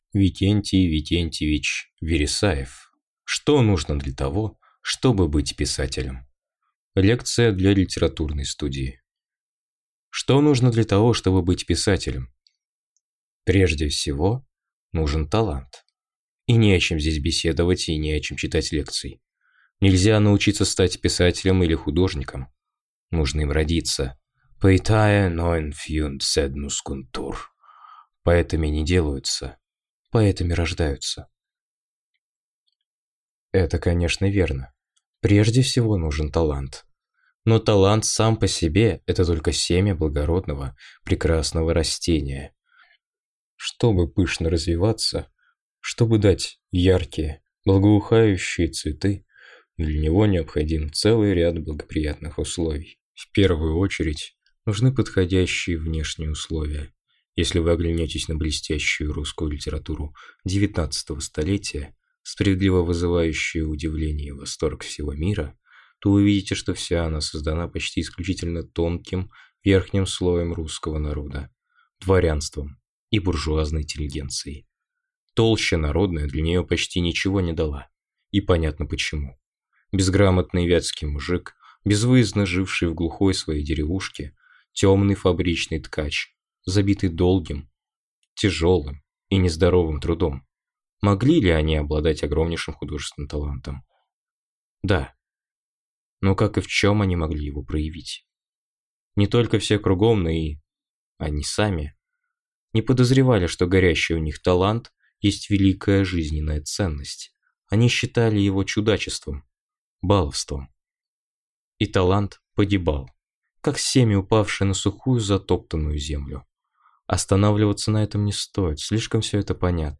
Аудиокнига Что нужно для того, чтобы быть писателем?